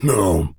Male_Grunt_Hit_09.wav